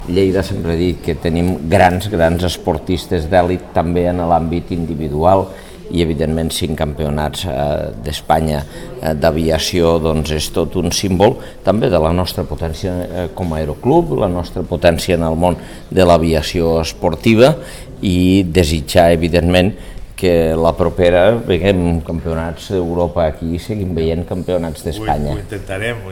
L’alcalde de Lleida, Àngel Ros, ha rebut avui al Palau de la Paeria al pilot de vol lleidatà
Arxiu de so Àngel Ros on felicita el pilot de rallys aeris